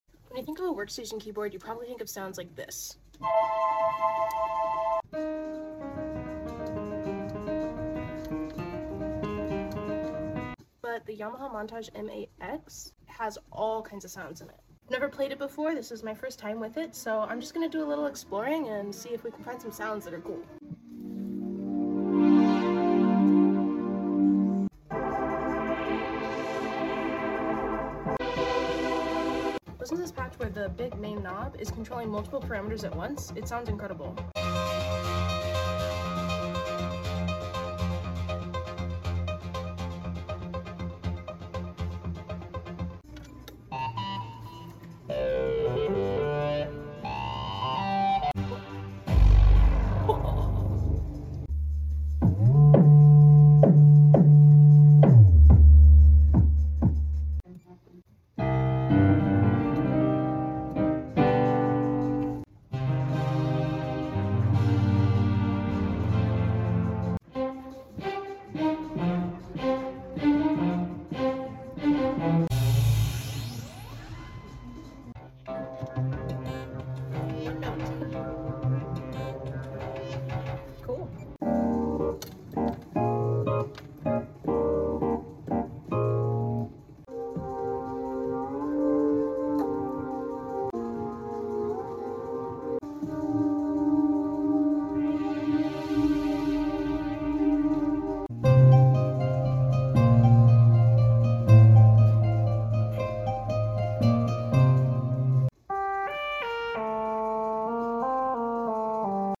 Playing the new Yamaha Montage sound effects free download